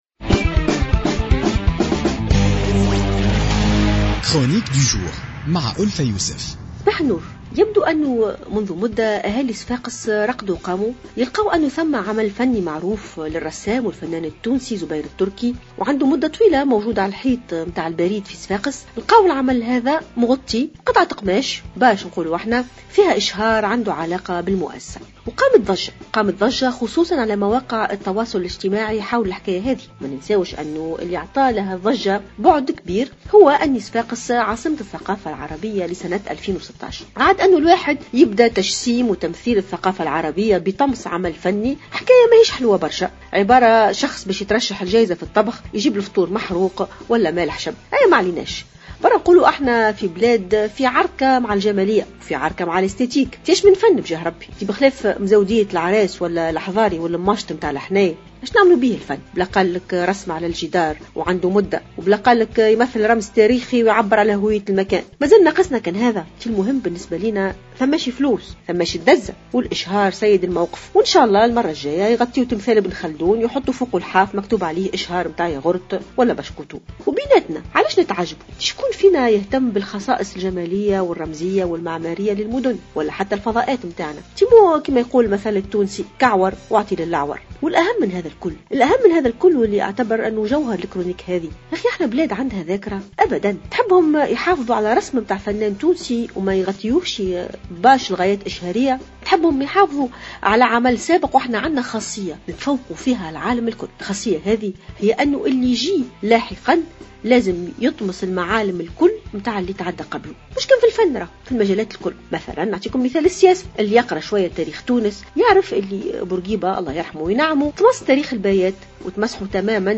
تطرقت الباحثة ألفة يوسف في افتتاحية اليوم الجمعة 4 مارس 2016 إلى الموضوع الذي أثار جدلا في الأيام الأخيرة والمتعلق بإتهام خبراء وباحثين في الاقتصاد لمسؤولين في مجلس التحاليل الإقتصادي التونسي المتصل برئاسة الحكومة بأنهم نقلوا جزء كبير من التقرير الإقتصادي للملكة المغربية لسنة 2011.